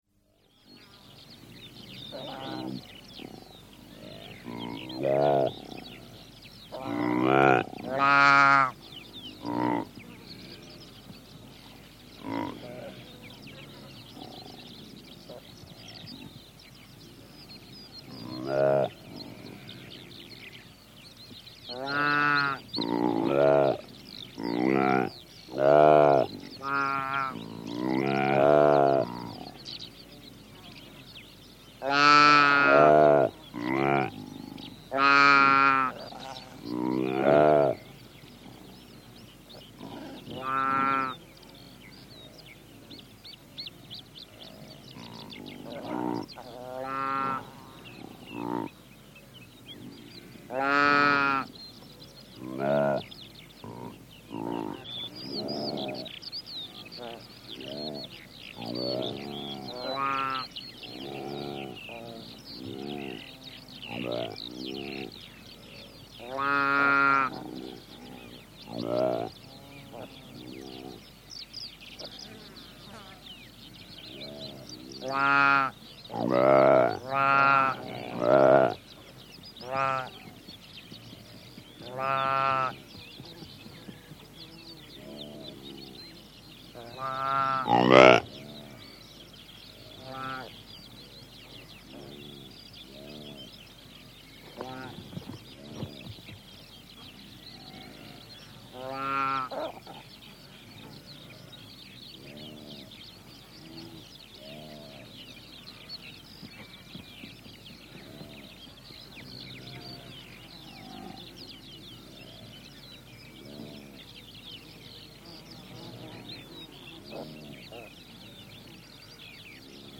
На этой странице собраны разнообразные звуки сайги – от голосовых сигналов до шумов, которые издают эти степные антилопы.
Голос самки сайги и теленка, пение жаворонков в казахстанской степи